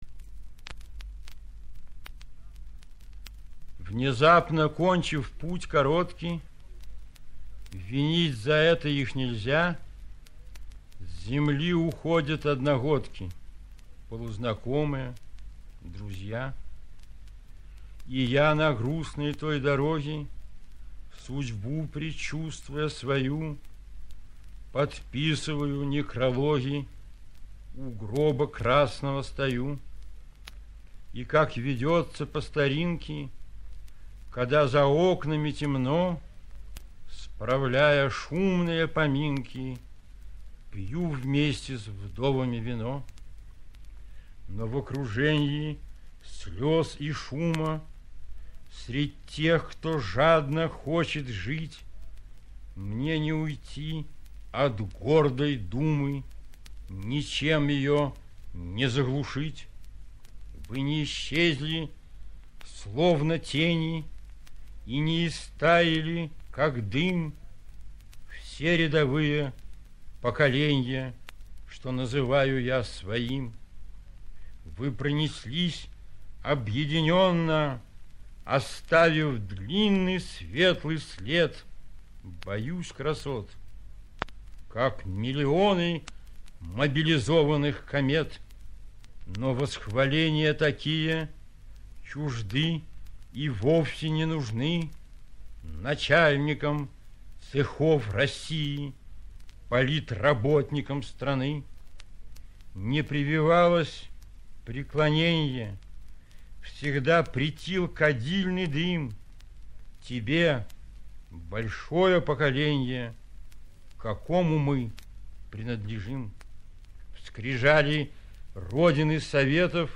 1. «Ярослав Смеляков – Вы не исчезли (читает автор)» /
yaroslav-smelyakov-vy-ne-ischezli-chitaet-avtor